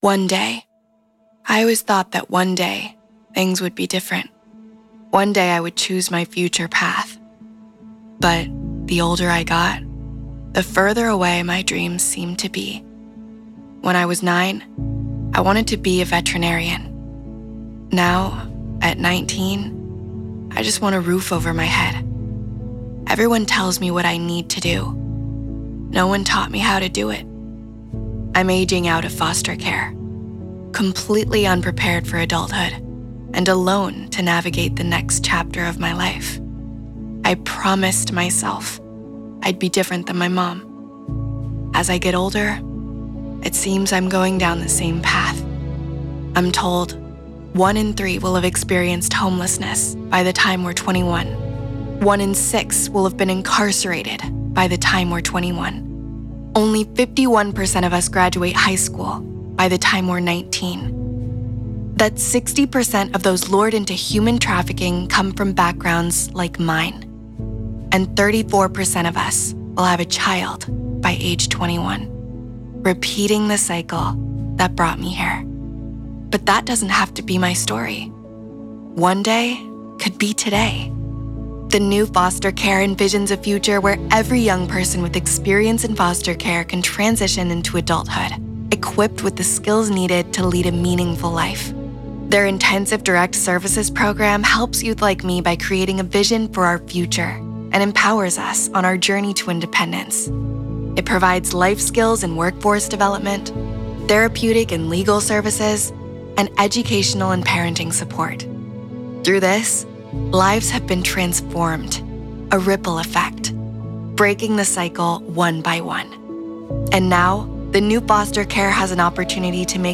A young, fresh, feminine and soft voice with genuine warmth and clarity
Soft, Emotional, Captivating | Nonprofit Explainer
bchipmedia One Day VO_MSTR with MUSIC.mp3